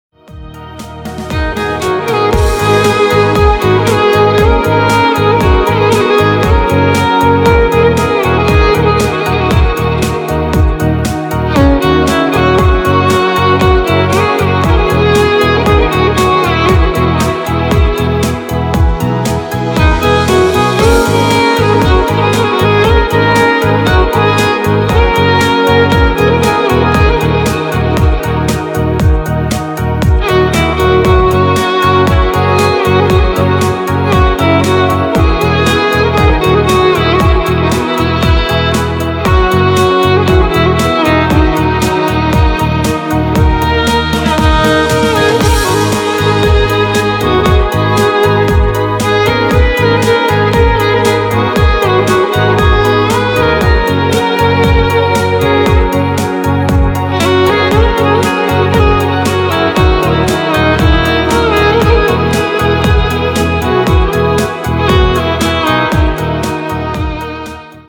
ژانر: بی کلام
آهنگ بی کلام غمگین